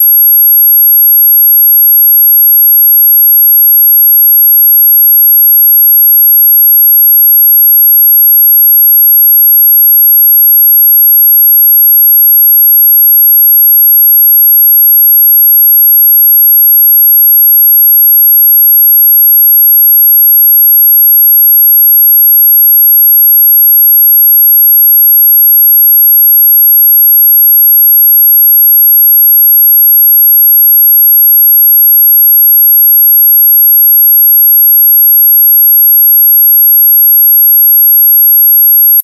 モスキート音は通常、高い周波数の音を指します。